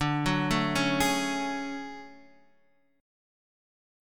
DM#11 chord